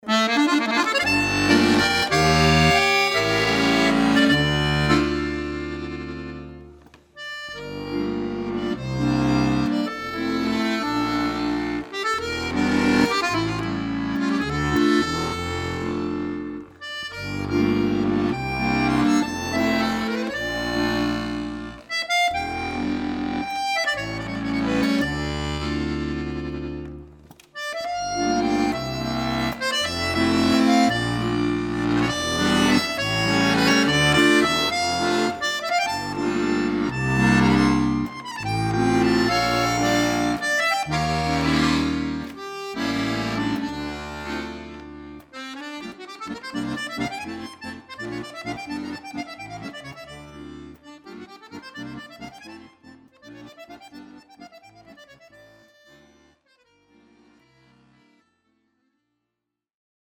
Klassische Musette